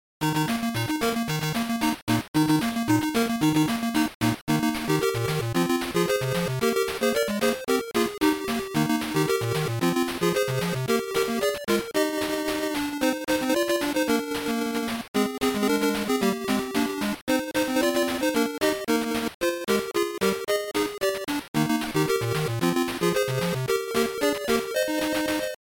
Músicas são limitadas mas absolutamente pegajosas